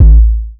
Waka KICK Edited (22).wav